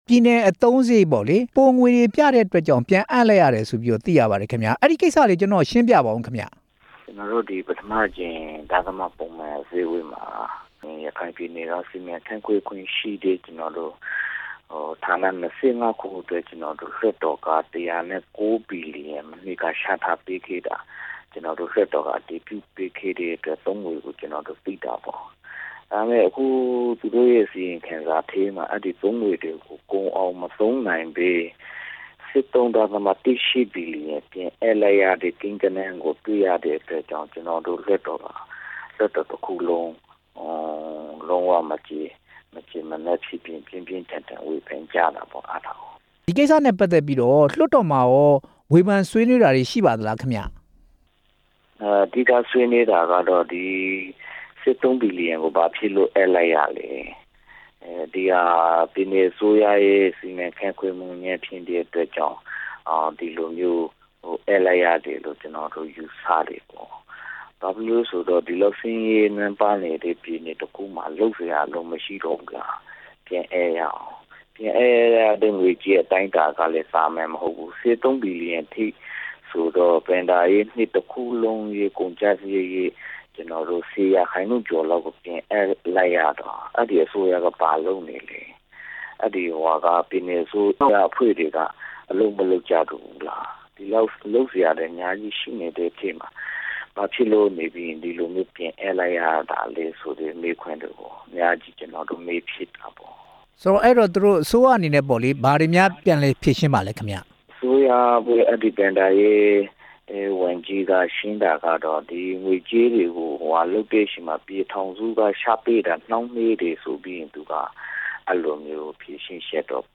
ရခိုင်ပြည်နယ်လွှတ်တော်ကိုယ်စားလှယ် ဦးအောင်မြကျော် နဲ့ မေးမြန်းချက်